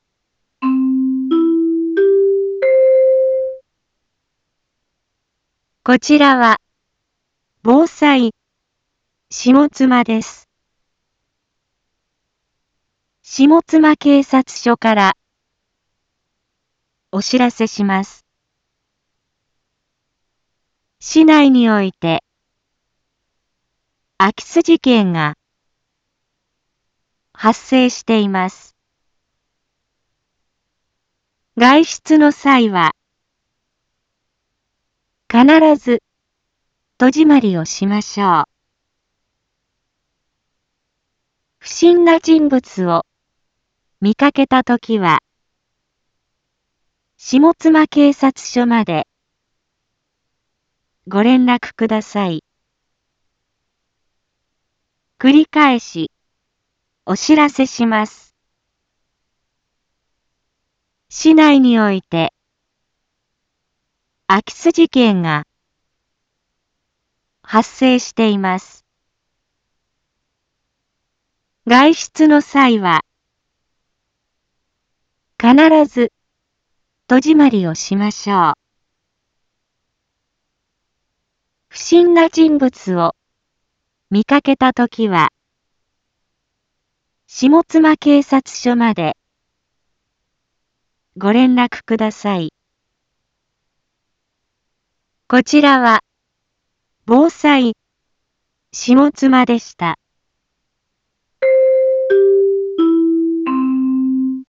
一般放送情報
Back Home 一般放送情報 音声放送 再生 一般放送情報 登録日時：2021-07-19 12:31:48 タイトル：空き巣被害にご注意を インフォメーション：こちらは、防災下妻です。